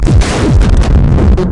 Tag: 音频 噪声 记录 搅动 样品 声音